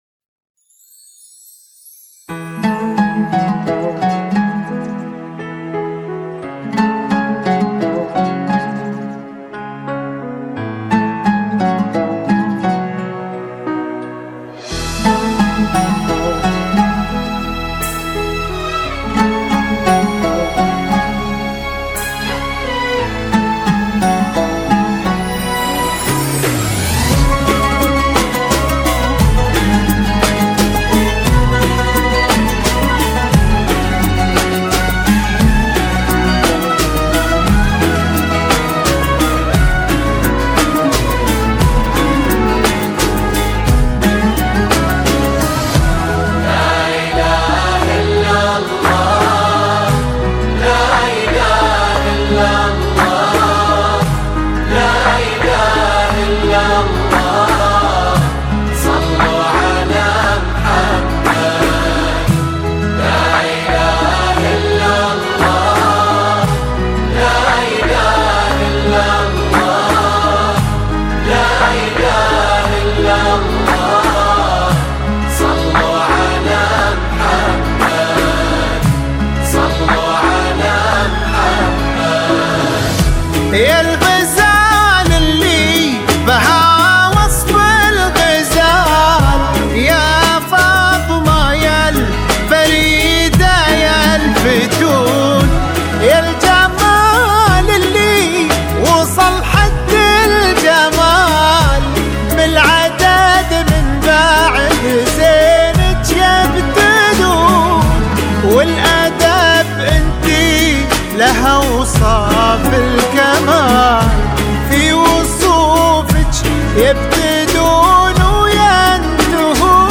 زفة عروس